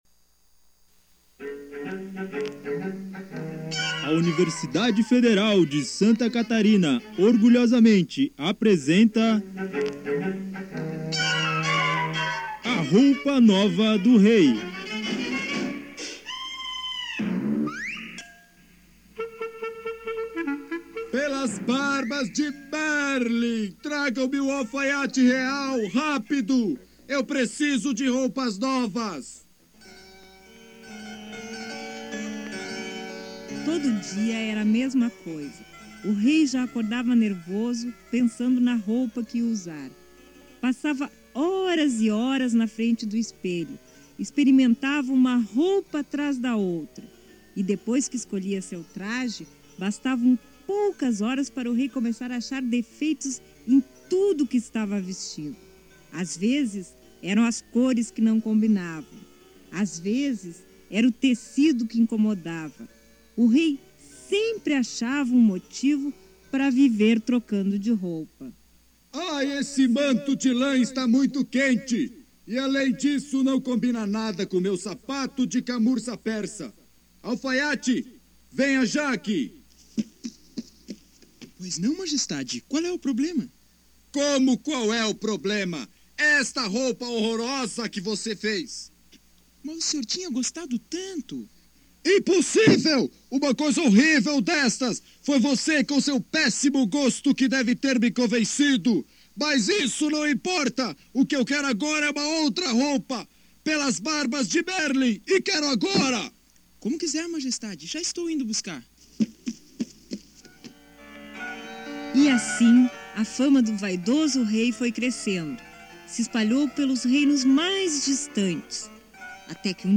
Radioteatro